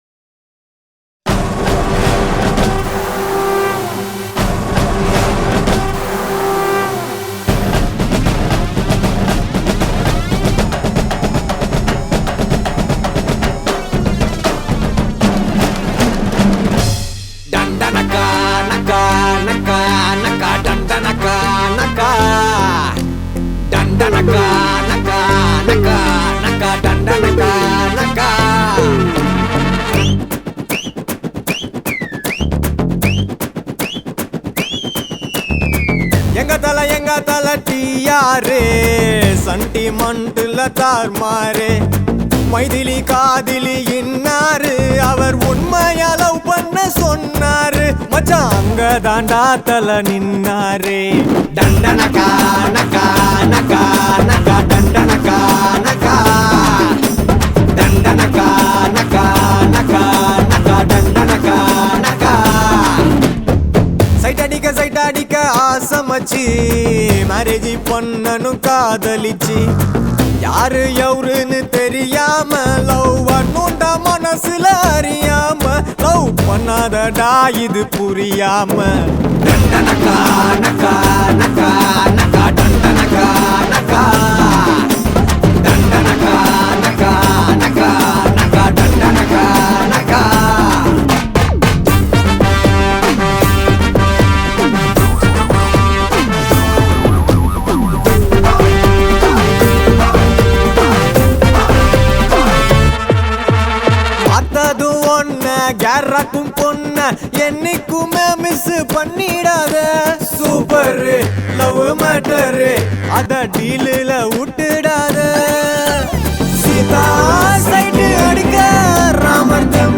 one massive kuthu number